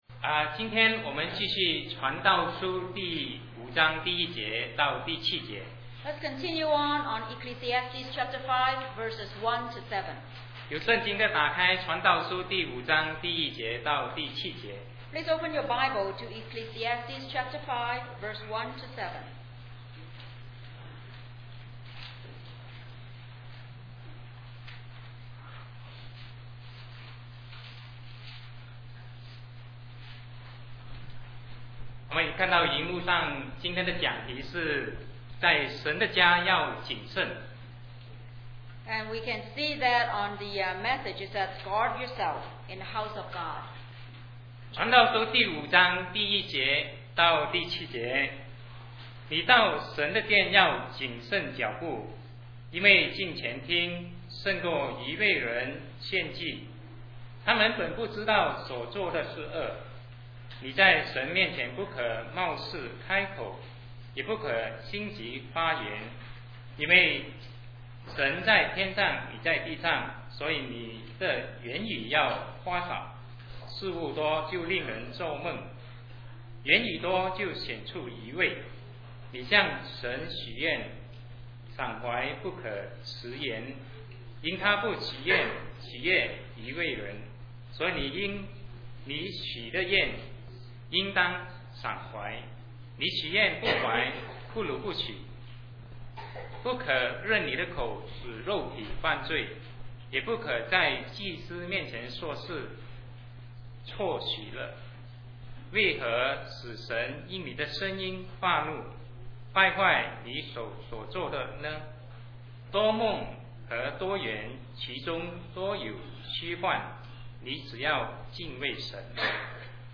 Sermon 2007-11-04 Guard Yourself in the House of God